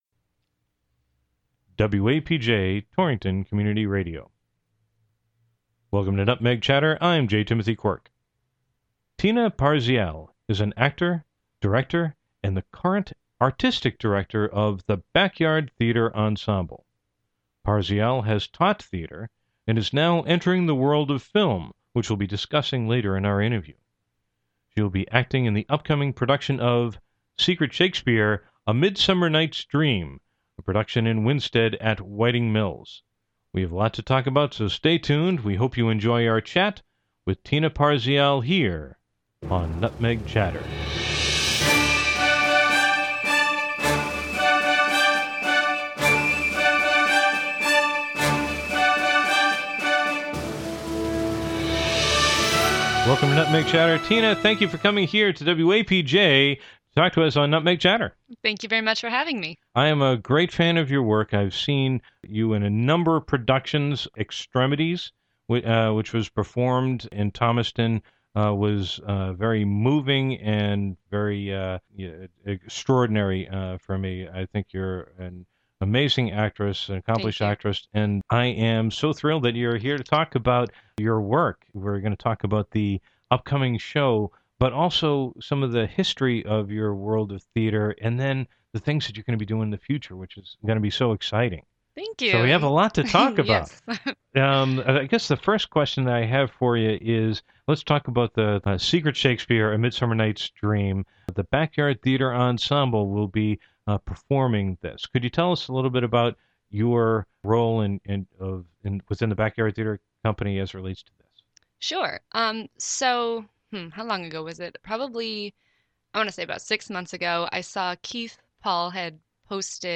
Radio Show